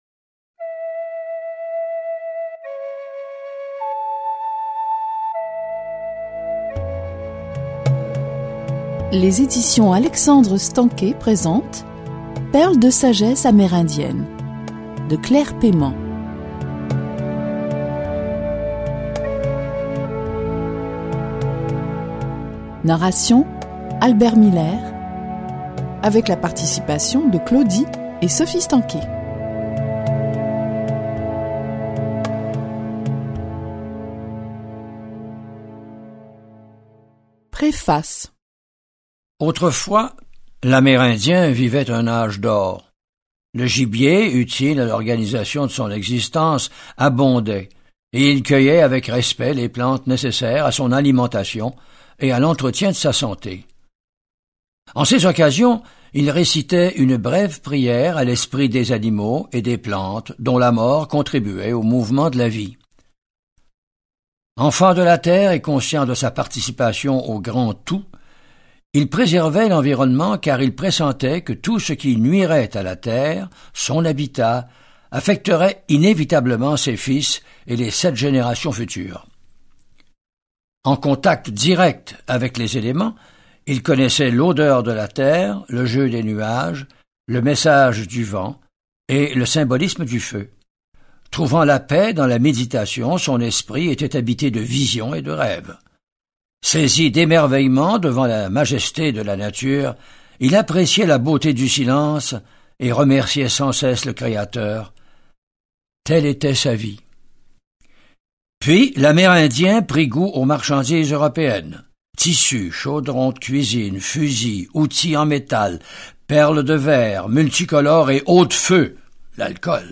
La sagesse amérindienne nous invite à analyser notre chagrin, à le vivre pleinement dans le moment présent et à croire en la bonté innée de l’être humain qui blesse tout simplement parce qu’il est lui-même profondément offensé. Ce livre audio présente une introduction à la spiritualité autochtone et à sa philosophie de vie fondée sur le respect du caractère sacré de toute forme de vie.